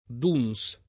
Duns [ingl. d 9 n @ ] top. (G. B.) — con pn. italianizz. [ dun S ]